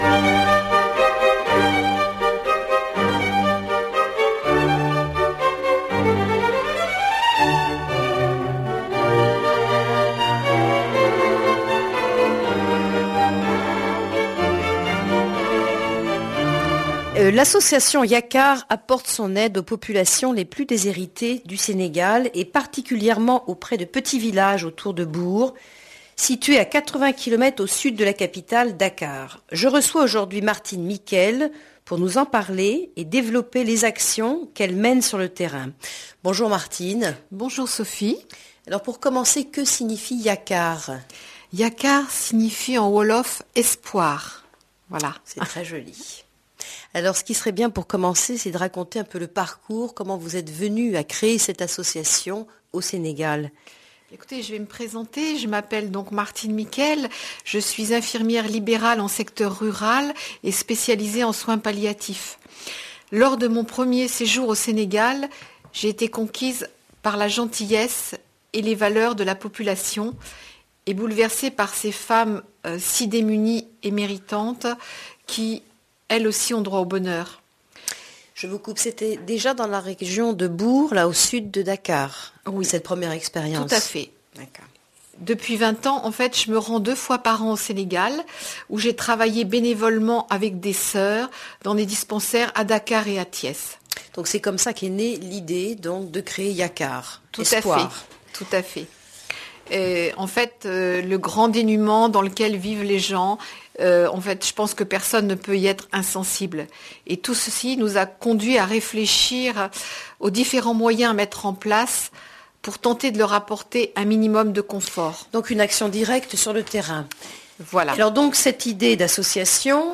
interview radio 2012